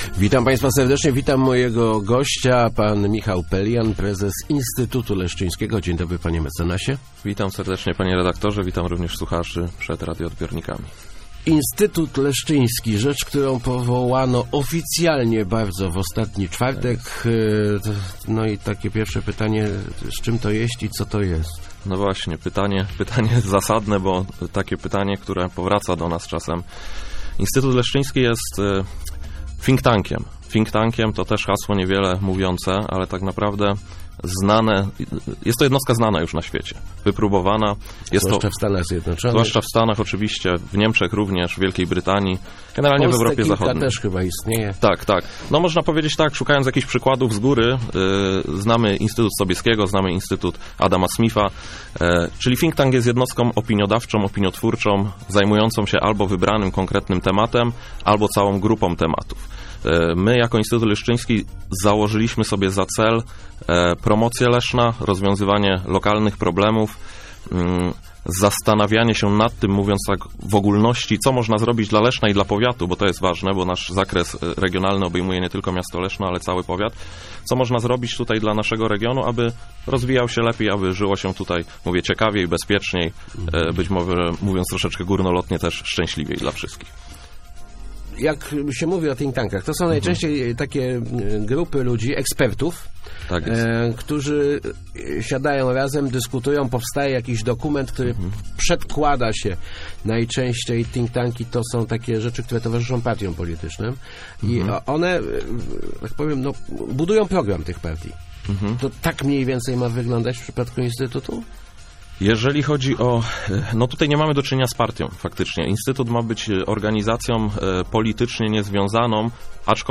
Rozmowach Elki